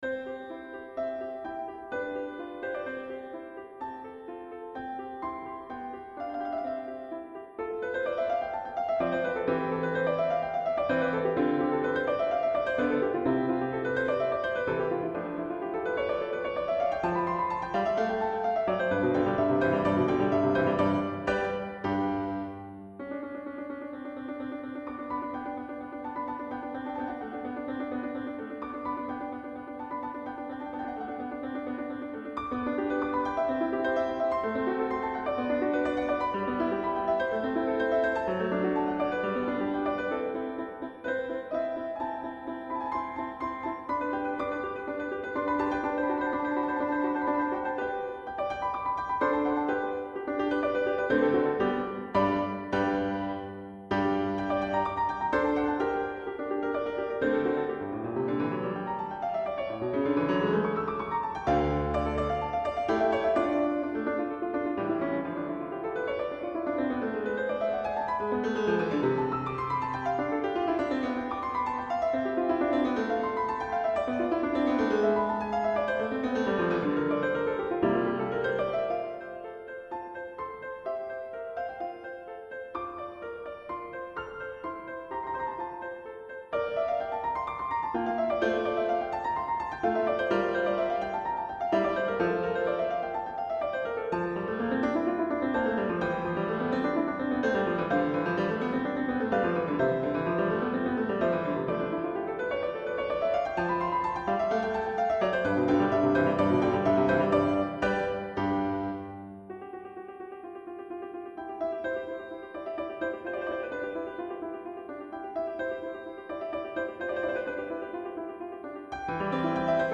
ラモーの方が普通に聴けるが、居心地の悪い響きが増えてしまうというのは同じ傾向。
ラモ―で耳が慣れてしまうと、平均律が調子っぱずれに聞こえ出す。